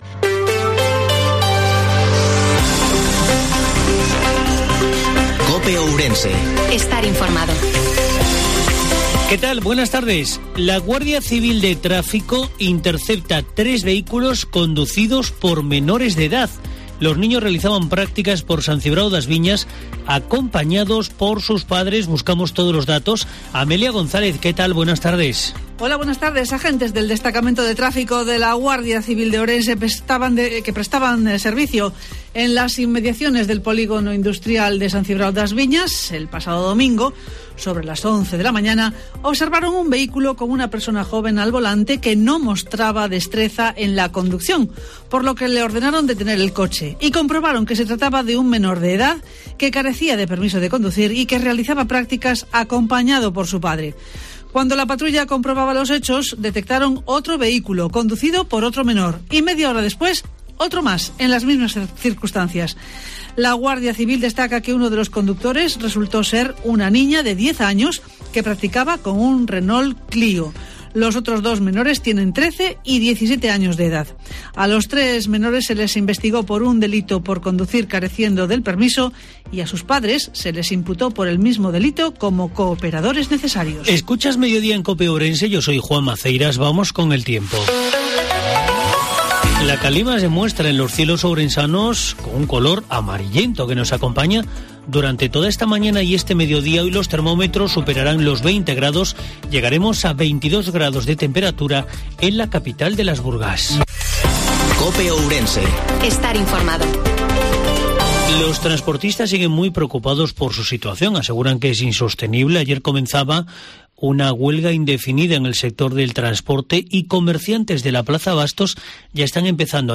INFORMATIVO MEDIODIA COPE OURENSE 15/03/22